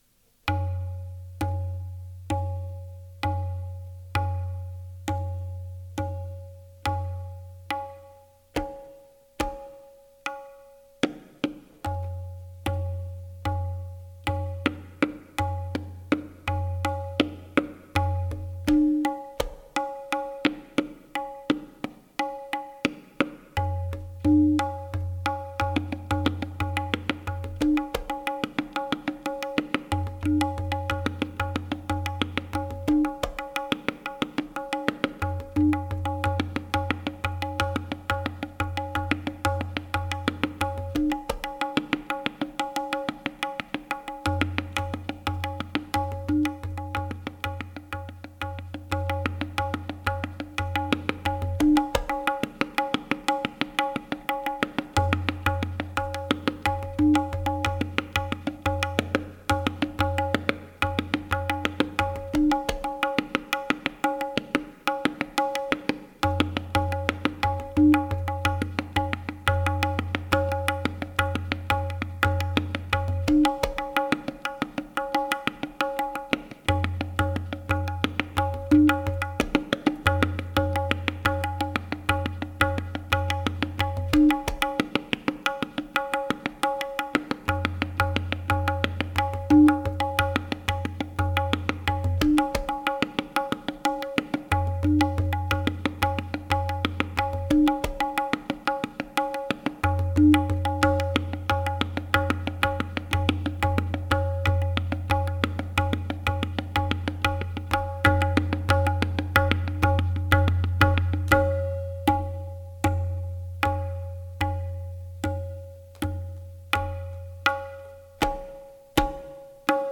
Tabla Solo
It was fun to get out there and play, and the crowd was generous and supportive.
tabla-recital